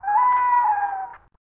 animalworld_vulture.ogg